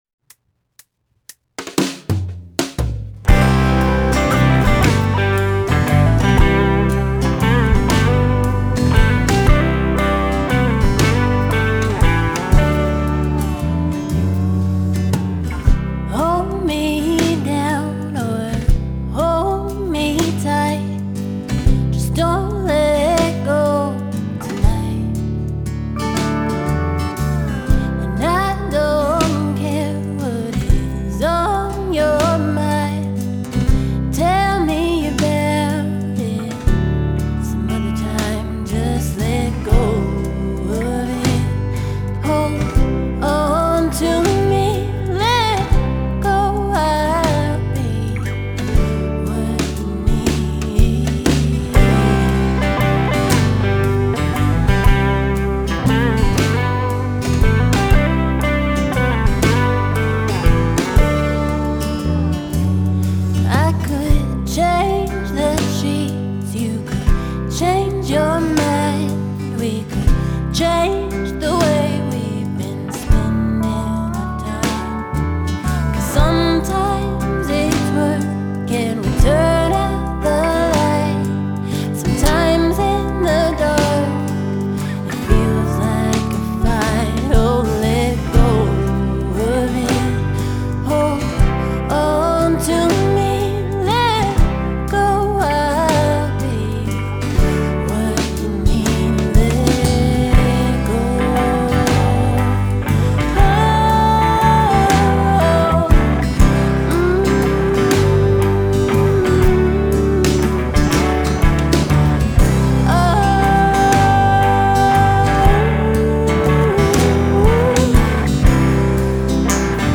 Genre: Folk Pop, Americana, Singer-Songwriter